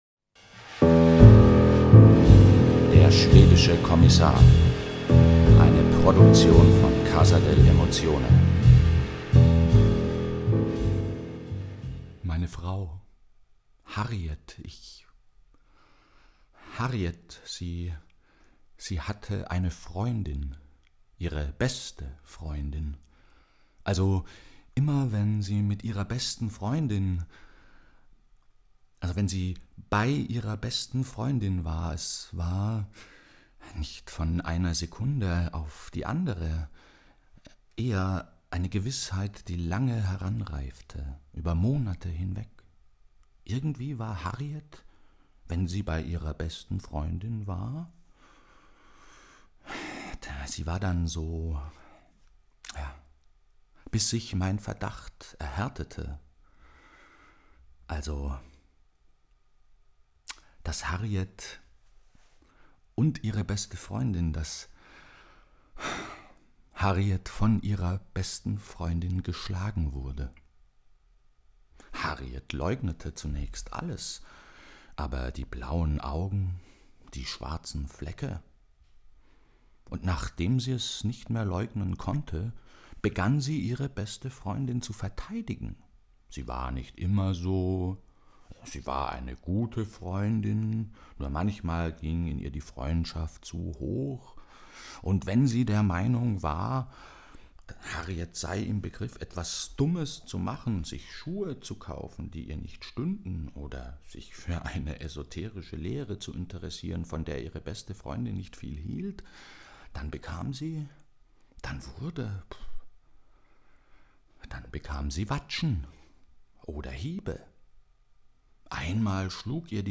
Hörprobe aus dem Hörspiel zum Theaterstück Der schwedische Kommissar
Gemeinsam mit Kommissar Harmson Bergund und dessen Assistenten Brusje denkt er über die ungelöste Mordserie rund um den Mann mit dem gelben Regenmantel nach. In einen slowburning Irrsinns-Monolog redet er sich um Kopf und Kragen.
Dieses Hörspiel entstand anlässlich der Aufführung des Theaterstücks Der schwedische Kommissar in der Garage-X, 2011.